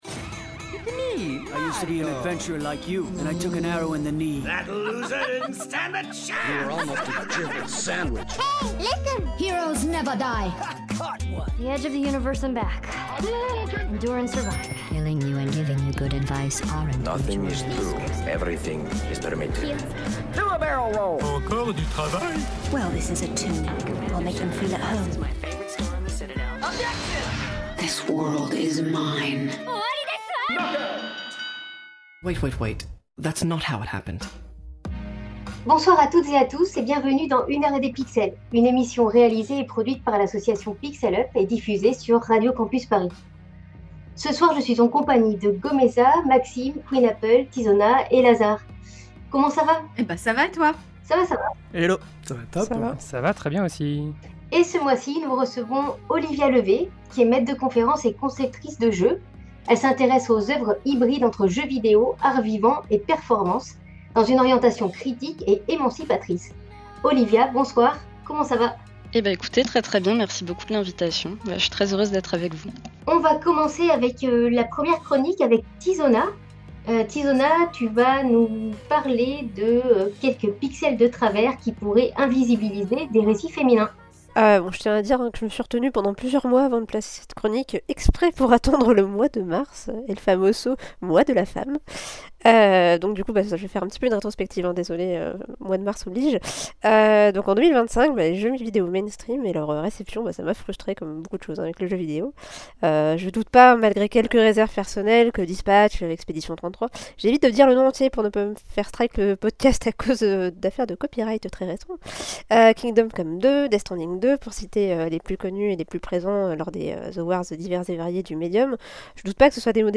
Émission diffusée le 21 mars 2026 sur Radio Campus Paris.